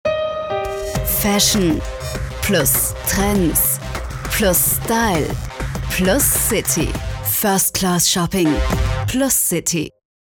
Meine Stimme ist warm, freundlich, kompetent, voll und dynamisch.
Seit 10 Jahren Profisprecherin
Kein Dialekt
Sprechprobe: eLearning (Muttersprache):
My voice is warm, friendly, competent and dynamic.